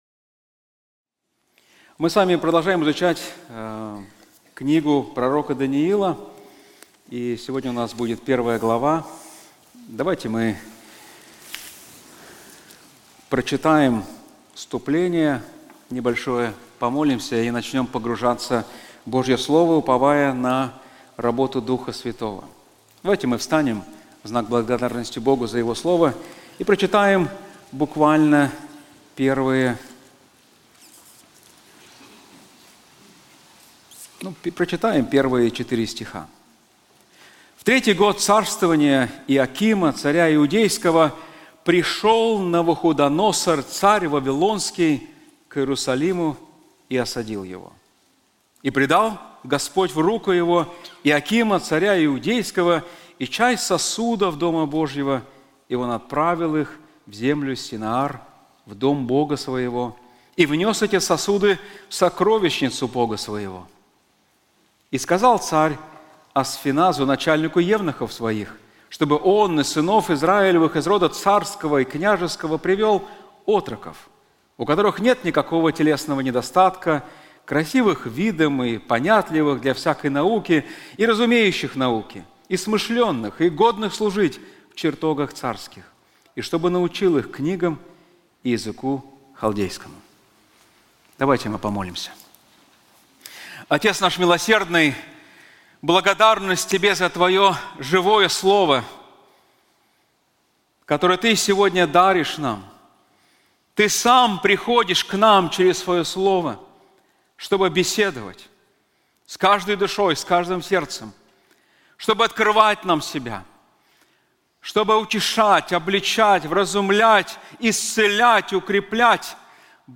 На конференции "Владыка истории" мы рассмотрим книгу пророка Даниила, каждая глава которой ясно показывает, что история находится в руках Бога. Лишь осознав, что нашим миром управляет не хаос, а всевластный Бог, мы сможем уверенно смотреть в будущее, оставаясь верными и усердными в служении.